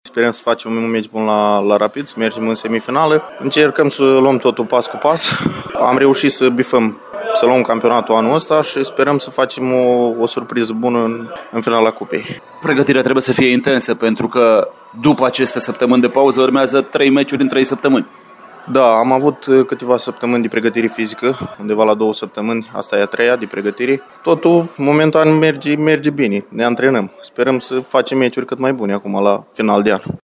Despre meciul cu Rapid a vorbit un jucător din pachetul de înaintare al Timișoarei